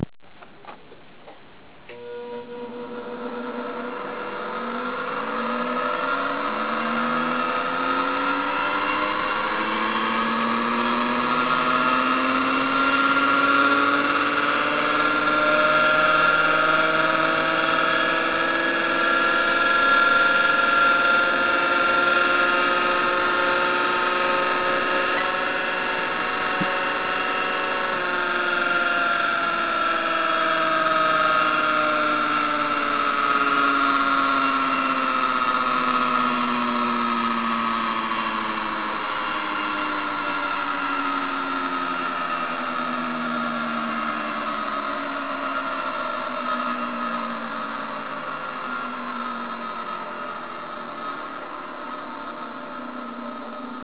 VF Generator No 48A, a big noisy brute generating 12 tones from 540 to 1980 cps. 431k (Circuit Diagram 183k)